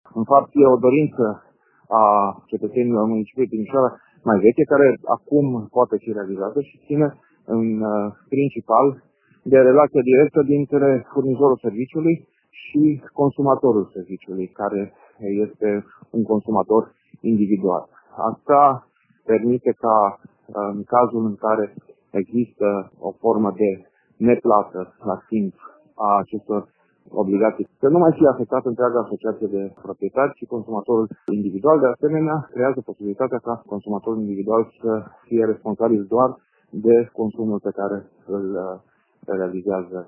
Viceprimarul Dan Diaconu spune că în felul acesta consumatorul individual este responsabil doar de consumul pe care îl realizează, fără să mai fie afectată întreaga asociație.